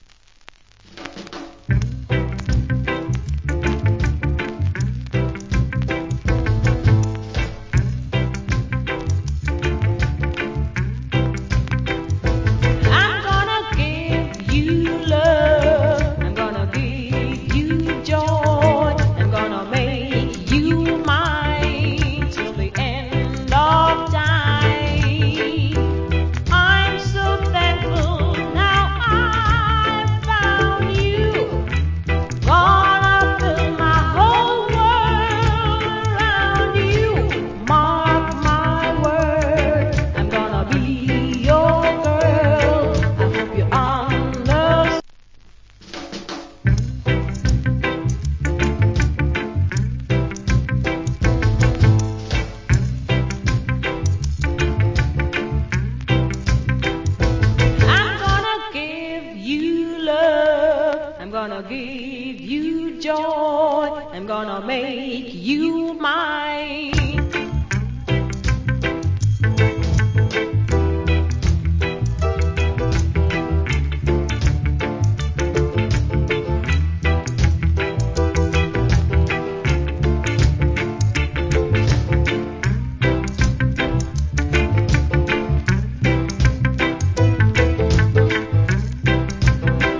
Female Rock Steady.